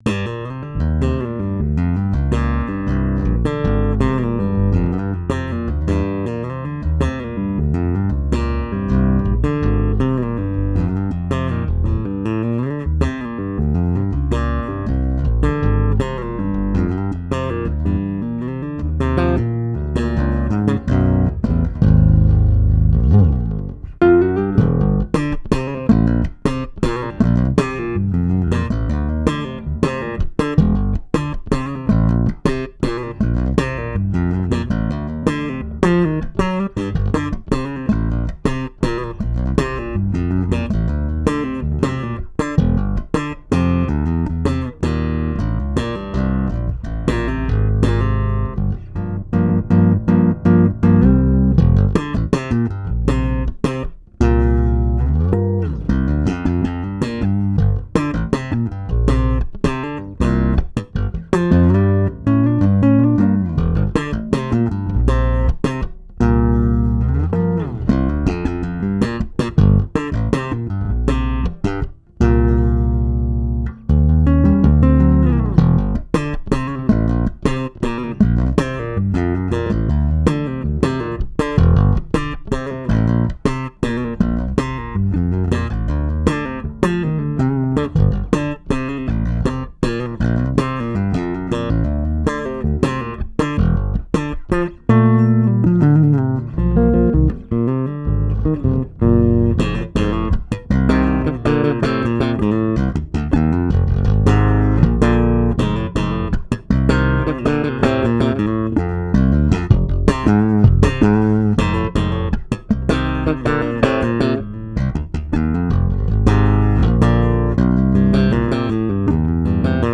The Application of Tapping Techniques in Compositions for the Solo Electric Bass